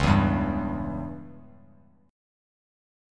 C#dim chord